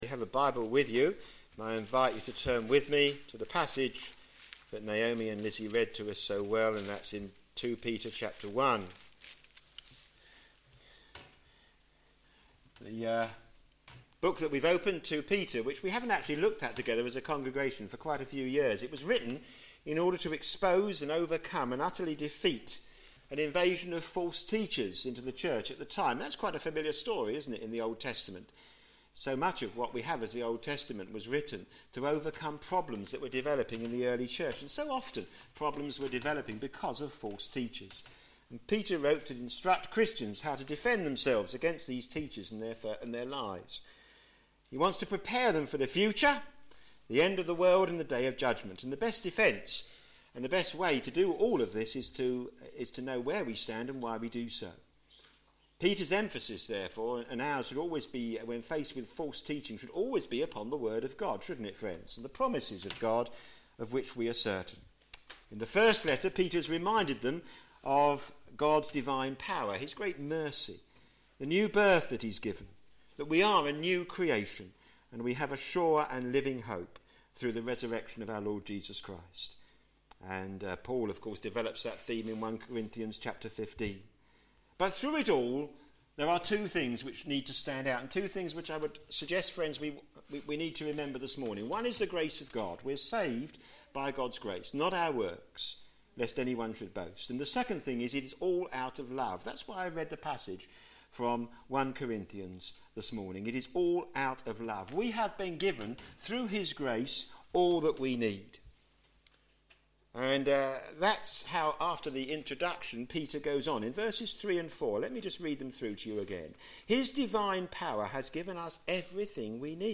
a.m. Service on Sun 17th Feb 2013
Theme: Christian virtues - Given by God Sermon In the search box below